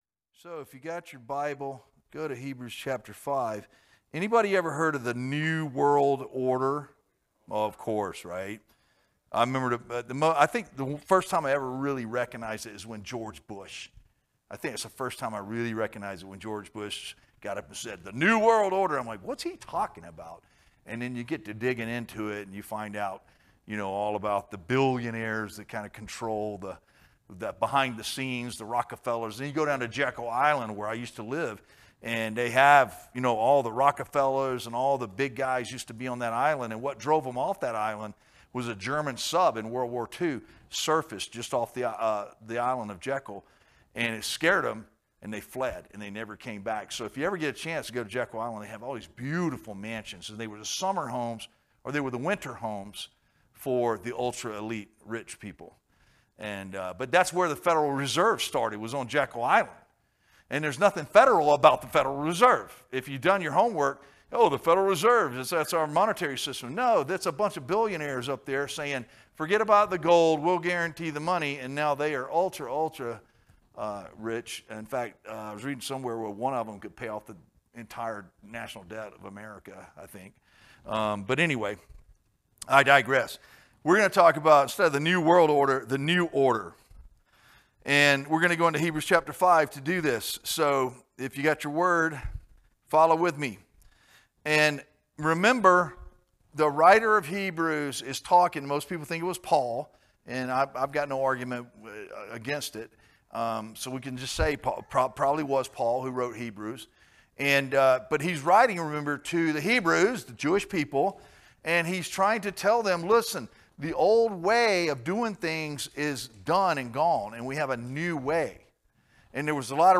teaches from the Book of Hebrews, Chapter 5